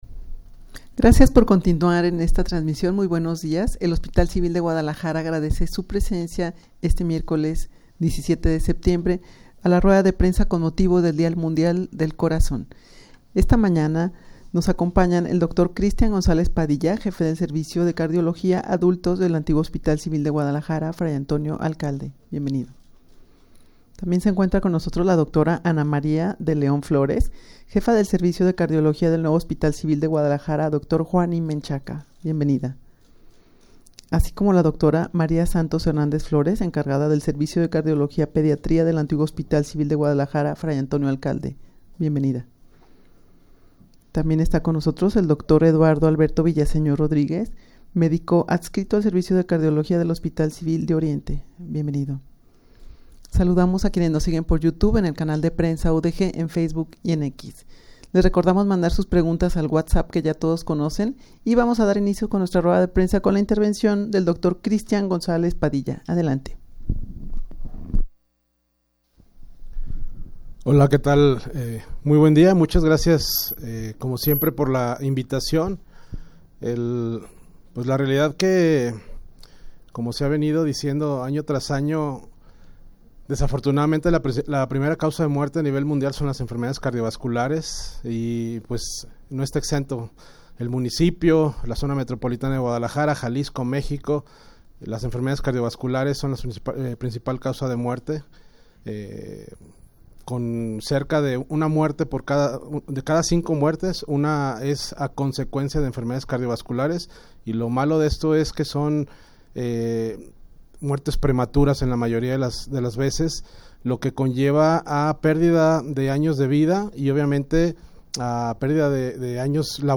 Audio de la Rueda de Prensa
rueda-de-prensa-con-motivo-del-dia-mundial-del-corazon.mp3